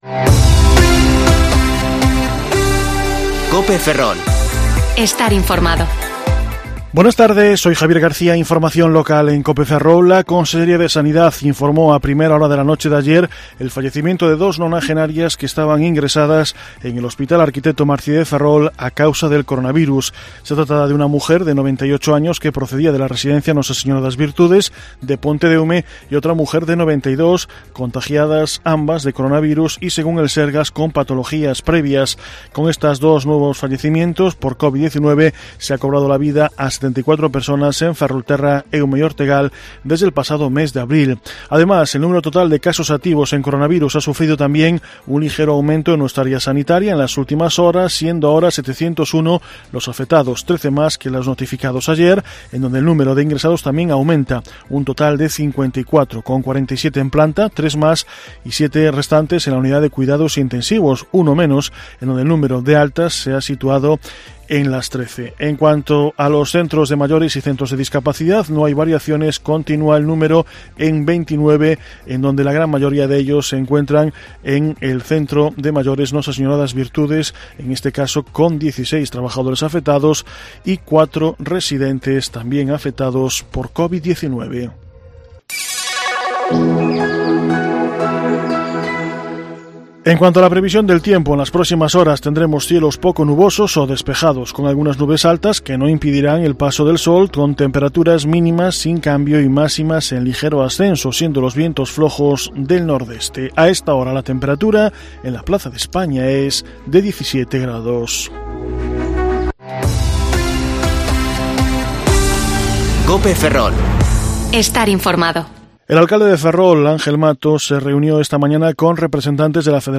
Informativo Mediodía COPE Ferrol 30/11/2020 (De 14,20 a 1430 horas)